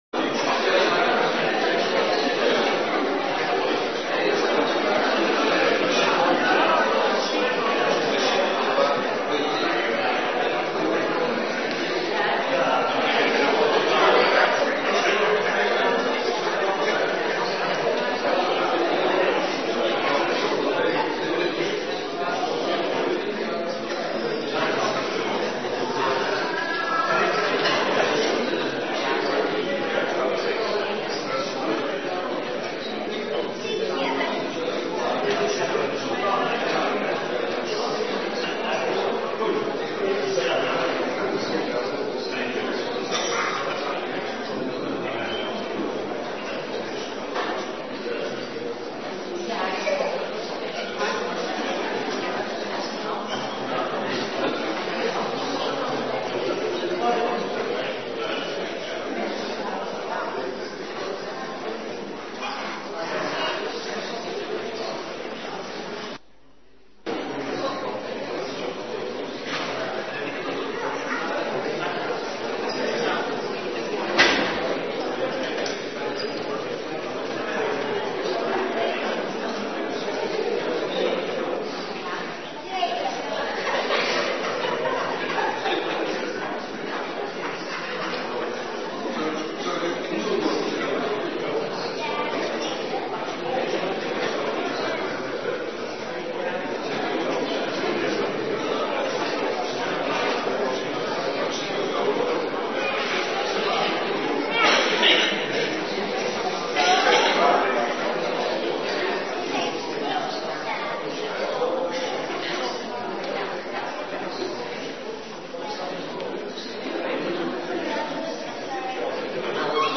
Orde van dienst voor zondag 3 februari 2019